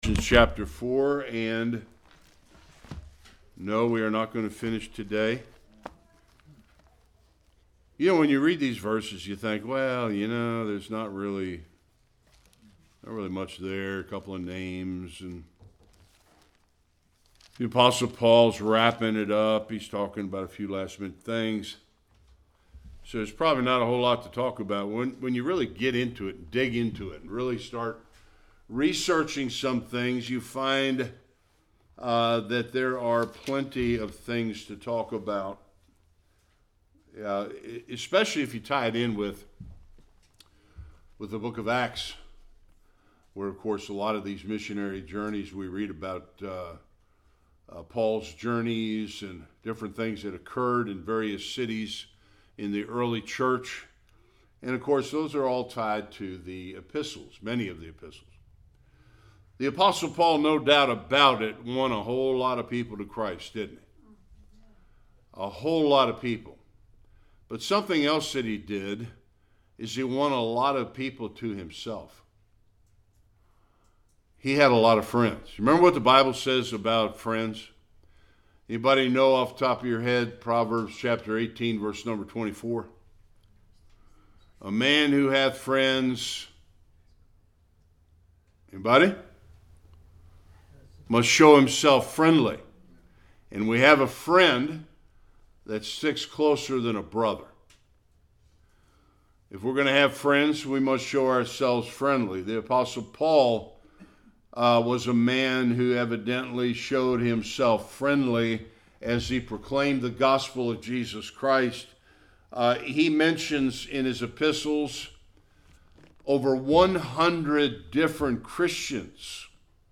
10-14 Service Type: Sunday Worship The Apostle Paul was blessed with many who helped him in ministry.